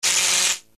LightningHitRod.mp3